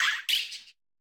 Cri de Pimito dans Pokémon Écarlate et Violet.